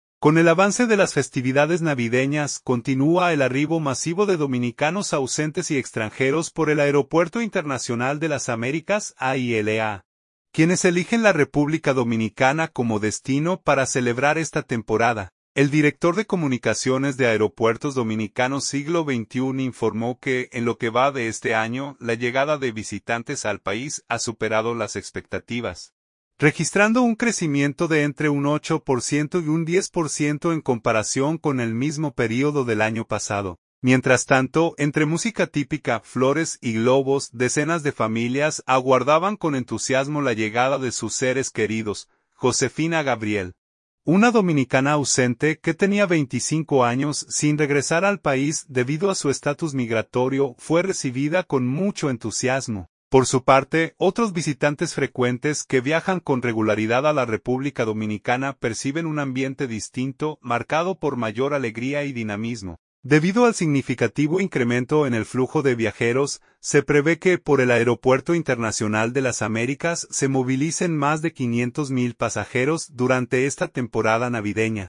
Mientras tanto, entre música típica, flores y globos, decenas de familias aguardaban con entusiasmo la llegada de sus seres queridos.